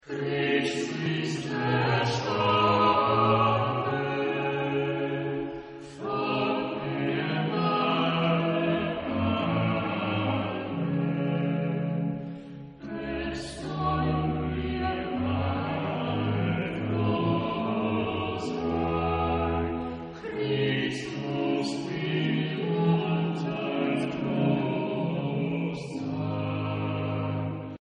Genre-Style-Forme : Choral ; Sacré
Type de choeur : SATB  (4 voix mixtes )
Instruments : Orgue (1) ad lib
Tonalité : mi mode de ré